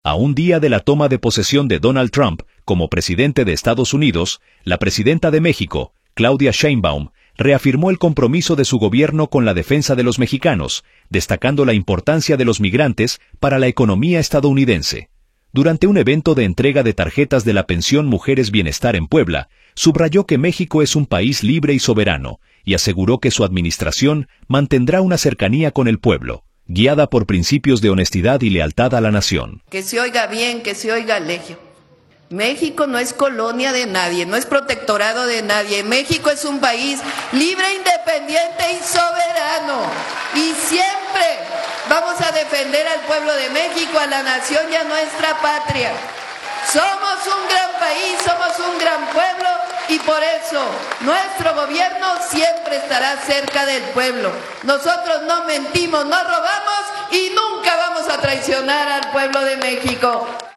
audio A un día de la toma de posesión de Donald Trump como presidente de Estados Unidos, la presidenta de México, Claudia Sheinbaum, reafirmó el compromiso de su gobierno con la defensa de los mexicanos, destacando la importancia de los migrantes para la economía estadounidense. Durante un evento de entrega de tarjetas de la Pensión Mujeres Bienestar en Puebla, subrayó que México es un país libre y soberano y aseguró que su administración mantendrá una cercanía con el pueblo, guiada por principios de honestidad y lealtad a la nación.